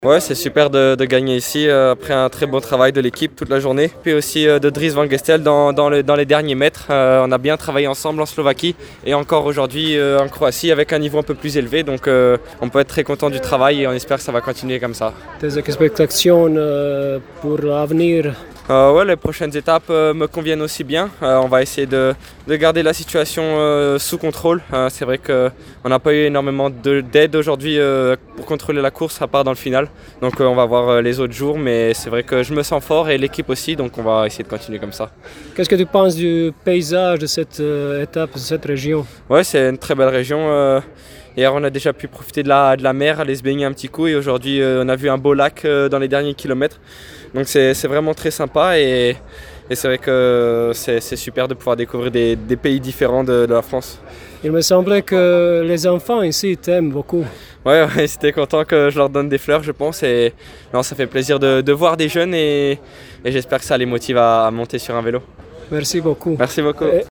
Nakon svečanosti proglašenja pobjednika i poduže procedure doping-kontrole, dočekali smo konačno 21-godišnjeg slavodobitnika i njegovu ljubaznu ekskluzivnu izjavu za portal Feratu i Hit radio: